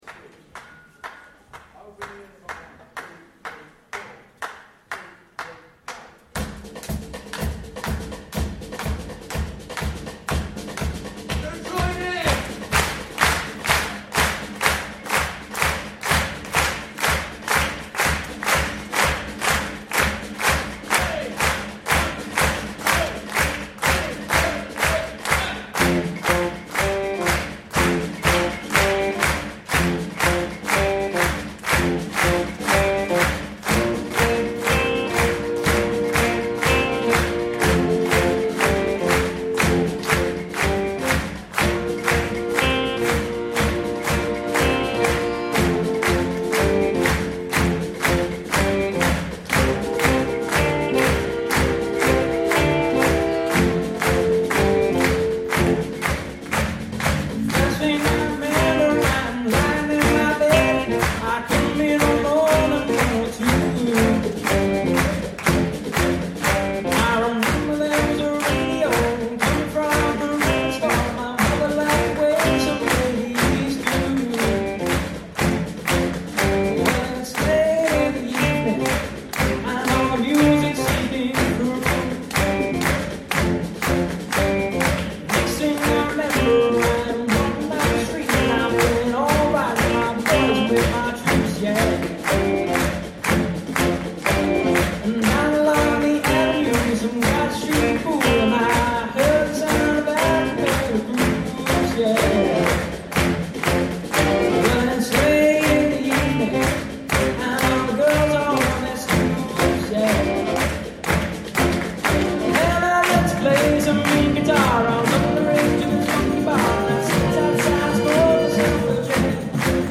Simon Balle Big Band
Summer Concert 2014